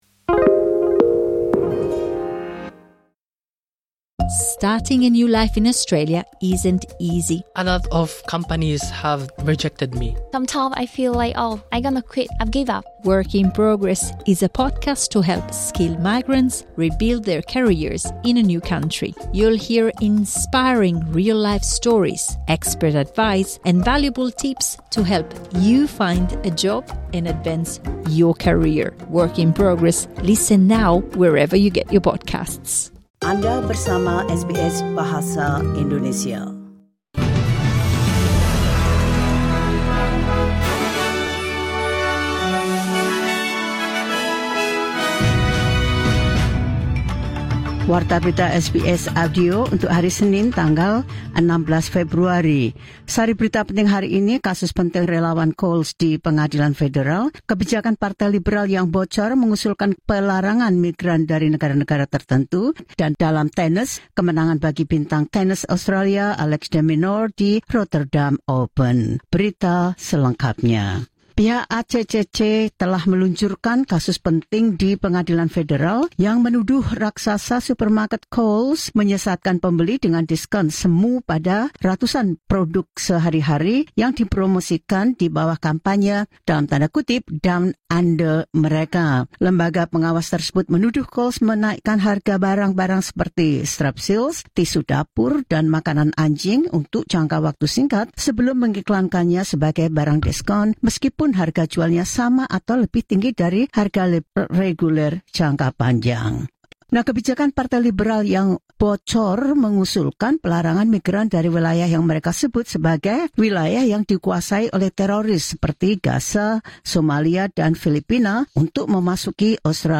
The latest news SBS Audio Indonesian Program – Mon 16 February 2026,